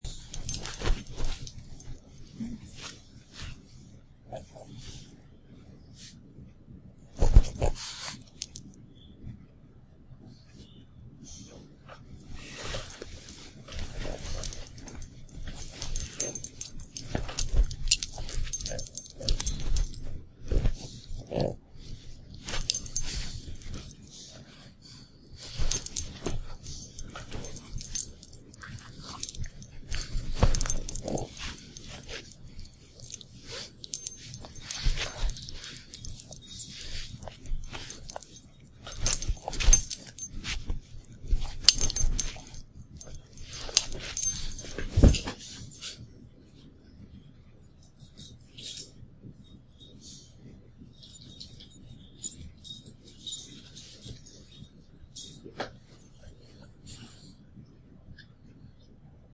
Dog Playing Sound Button - Free Download & Play